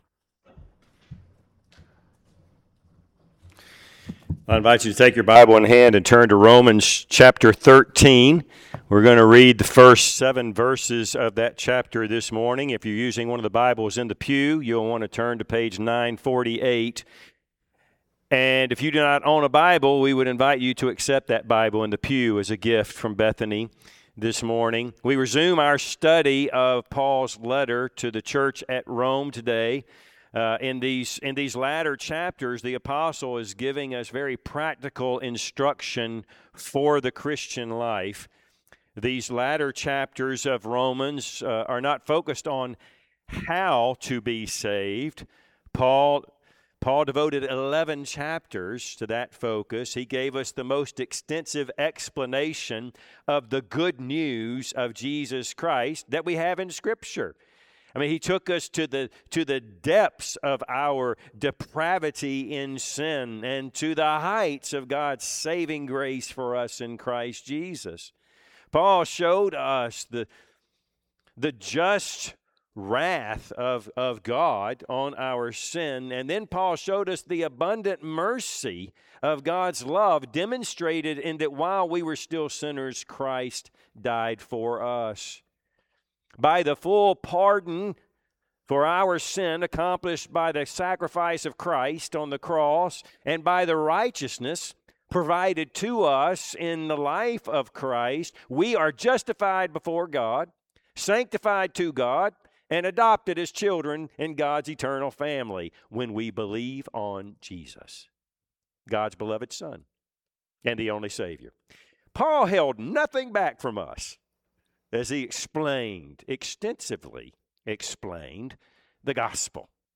Service Type: Sunday AM Topics: Christian living , government , politics